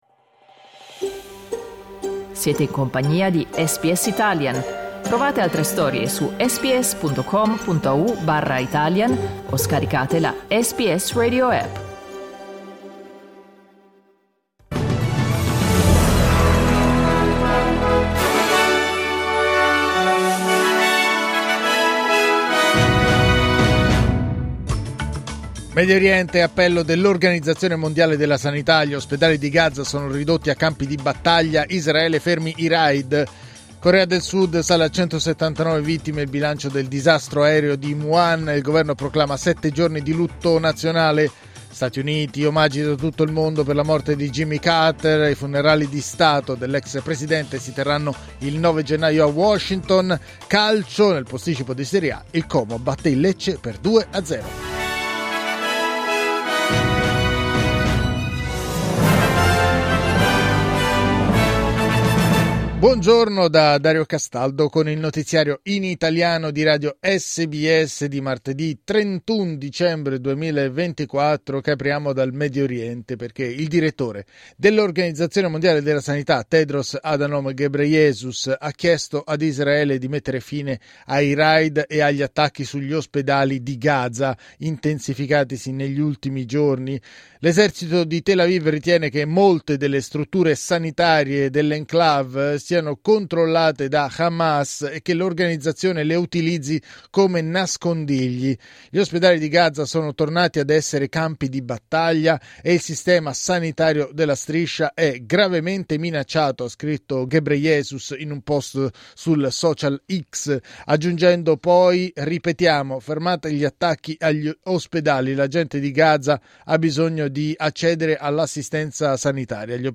Giornale radio martedì 31 dicembre 2024
Il notiziario di SBS in italiano.